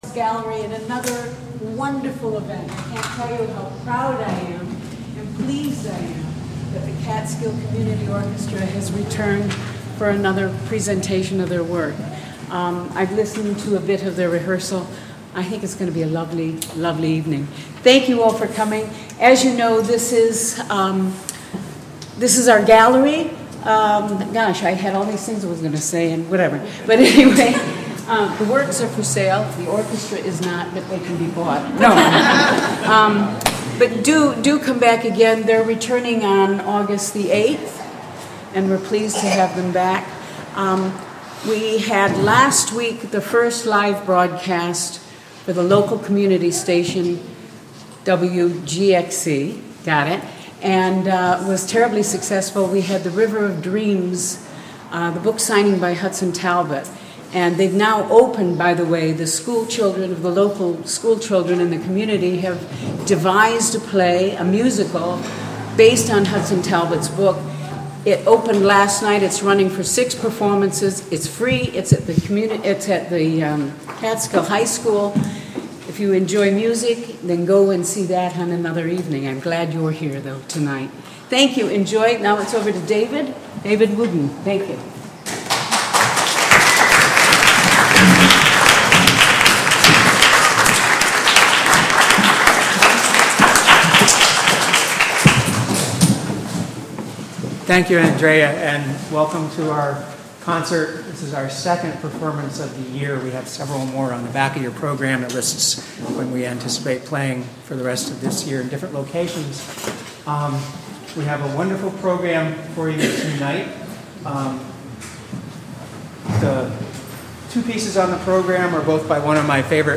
Catskill Community Orchestra on WGXC: May 16, 2009: 7pm - 8:30 pm
Online Radio broadcast from Union Mills in Catskill.